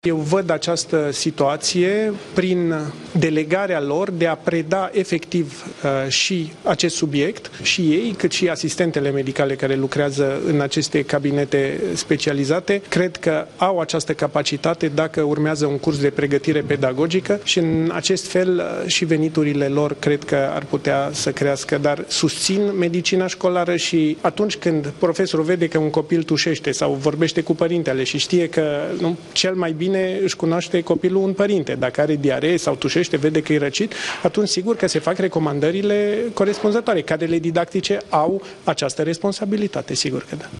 Cât despre educația sanitară, ministrul crede că aceasta ar trebui făcută de medicii și asistenții medicali din școli, însă triajul epidemiologic al copiilor va fi făcut de către profesori: